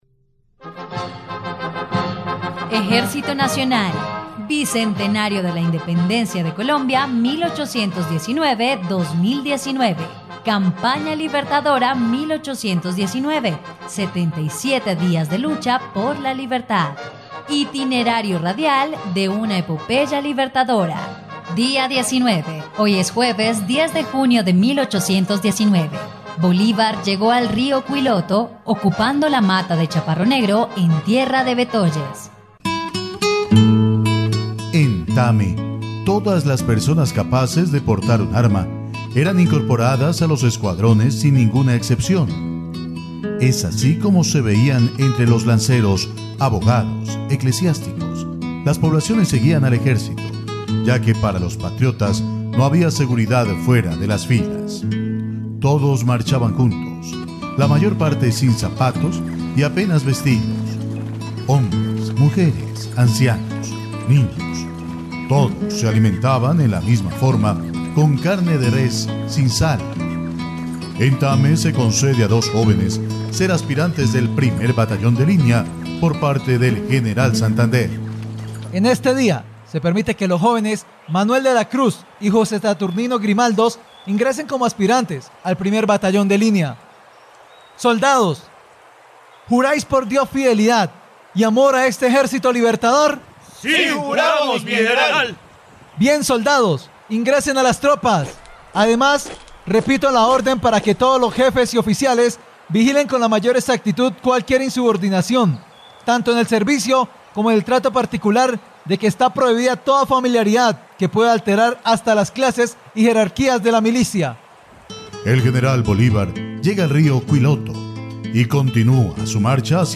dia_19_radionovela_campana_libertadora.mp3